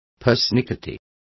Complete with pronunciation of the translation of persnickety.